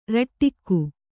♪ raṭṭikku